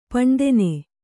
♪ paṇdene